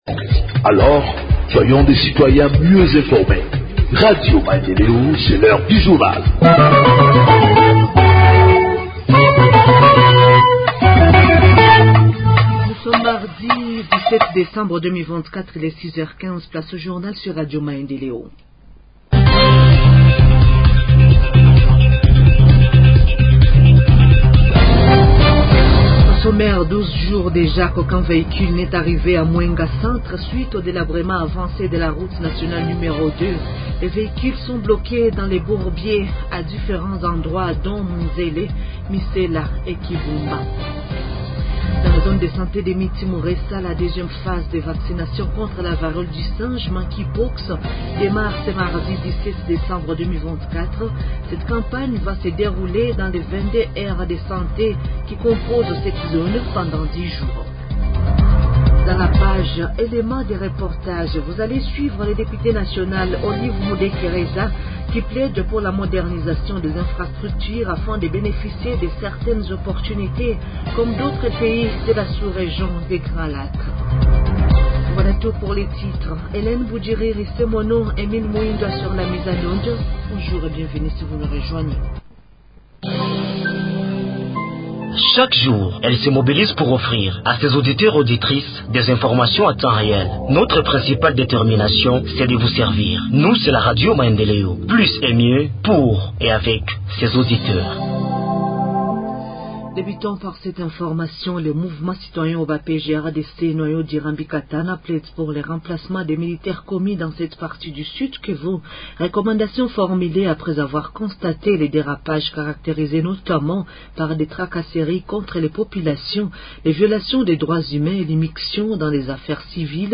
Journal en Français du 17 Décembre 2024 – Radio Maendeleo